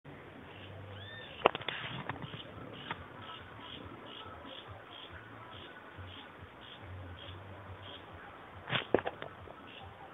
Hirundinea ferruginea bellicosa
Nombre en español: Birro Común
Localidad o área protegida: Las Juntas
Condición: Silvestre
Certeza: Observada, Vocalización Grabada